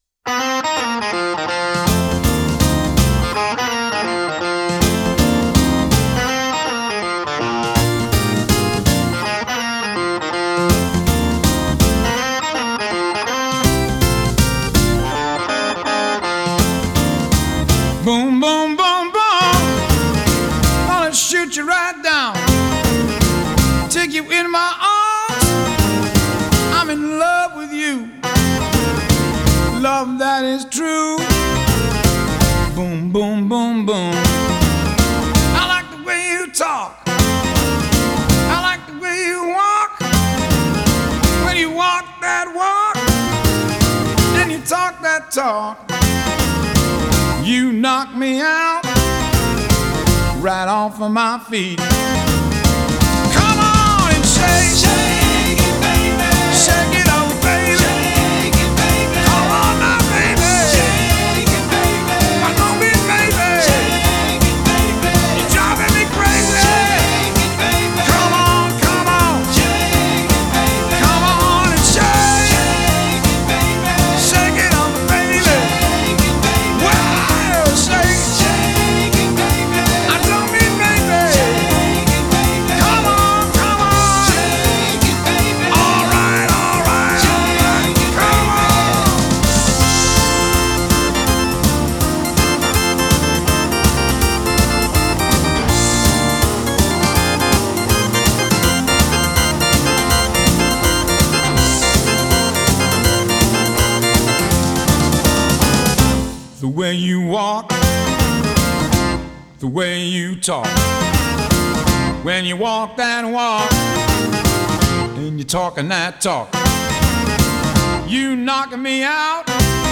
Жанр: психоделический рок, блюз-рок, Ритм-н-блюз, Рок
Genre: Blues, Rock